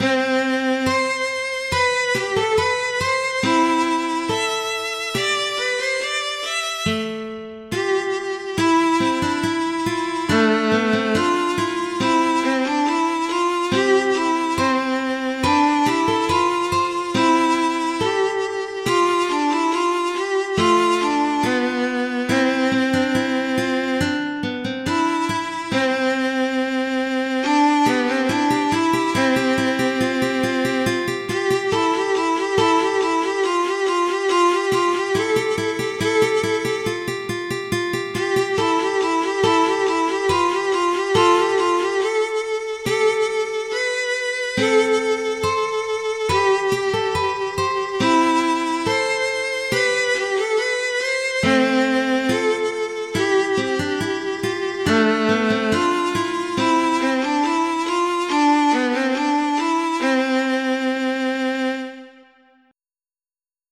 It has an interesting fugue-like quality.